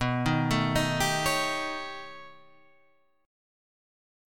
B Augmented 9th